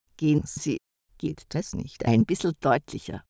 Schönbrunner Deutsch